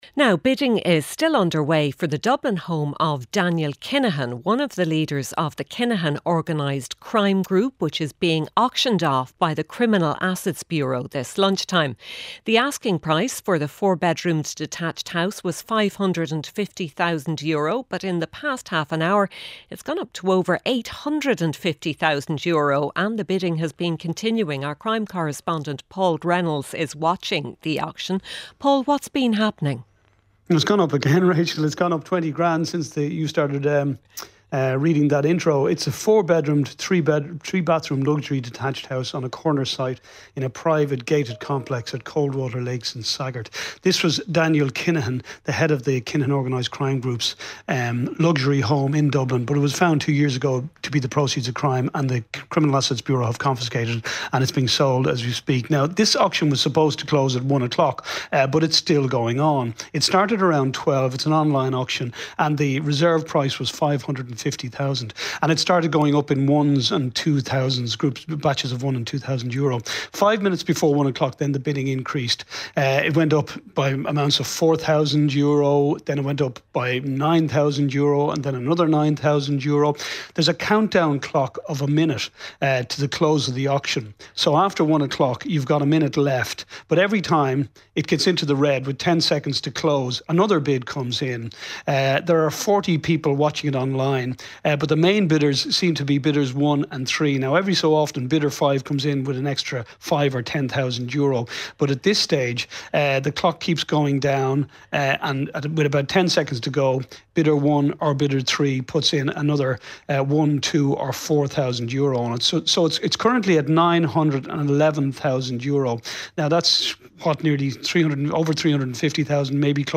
News, sport, business and interviews. Presented by Rachael English. Listen live Monday to Friday at 1pm on RTÉ Radio 1.